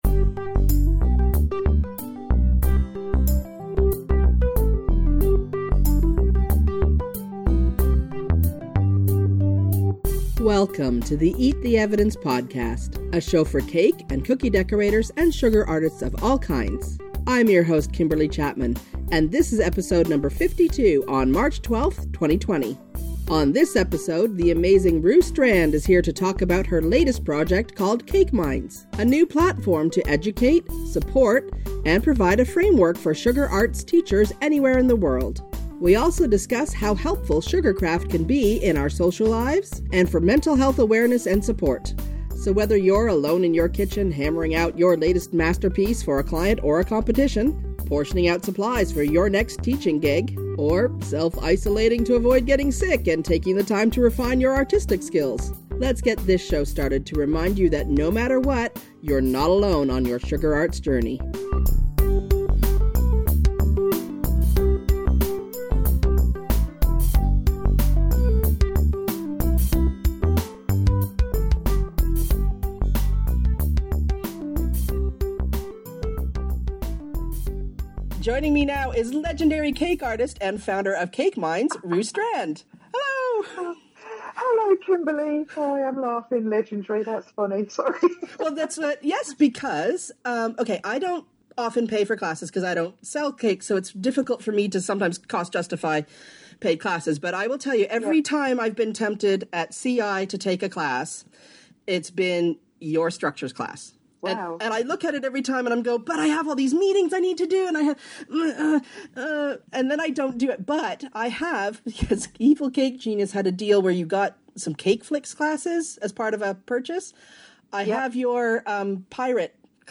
Music/Sound Credits The following songs and sound clips were used in this episode of Eat the Evidence.